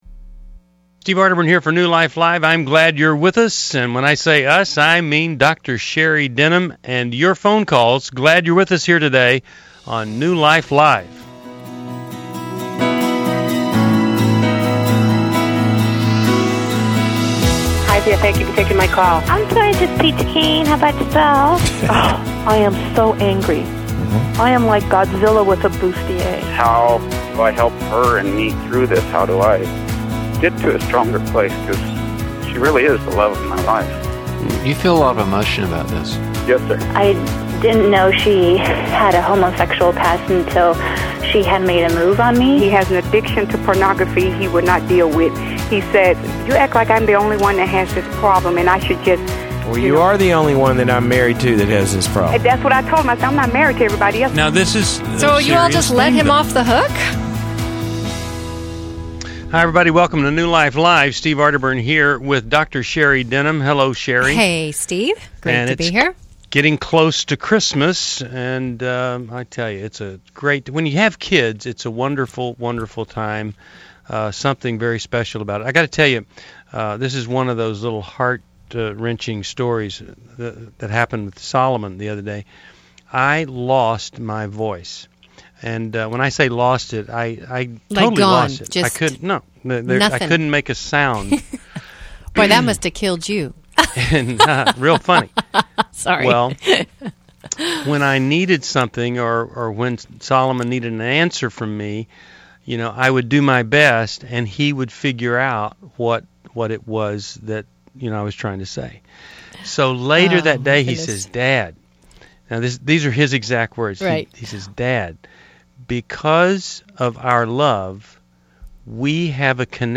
Caller Questions: My husband thinks I am borderline, but I’m not. Shall we separate for good?